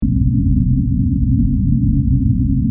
mrairflow2.mp3